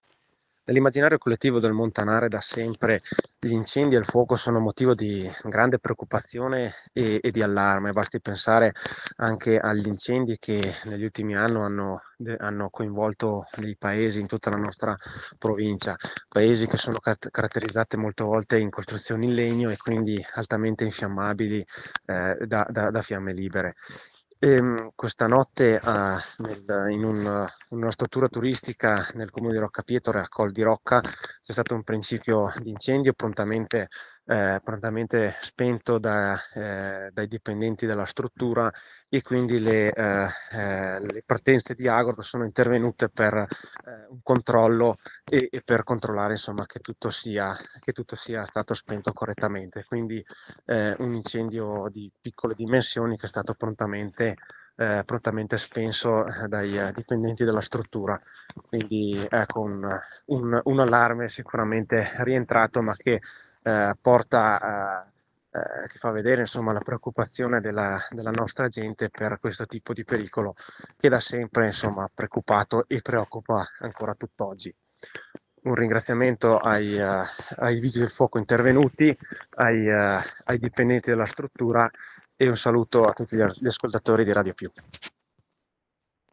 IL SINDACO DI ROCCA PIETORE, VALERIO DAVARE